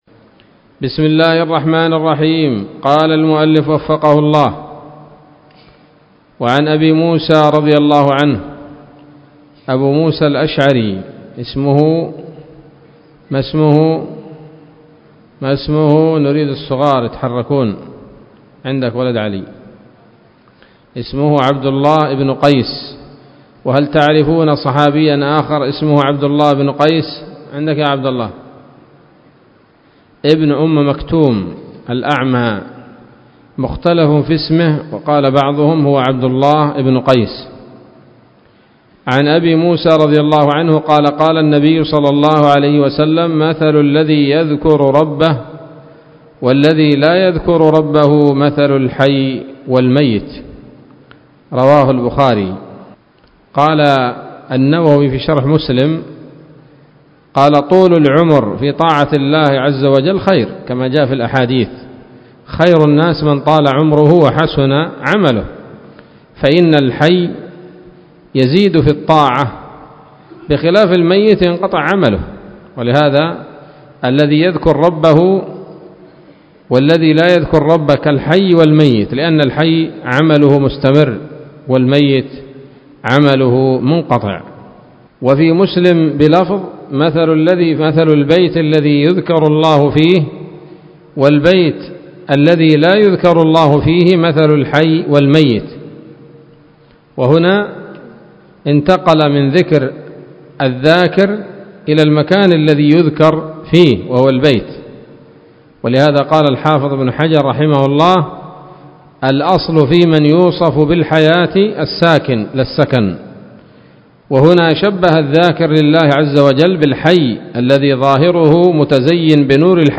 الدرس الرابع من رياض الأبرار من صحيح الأذكار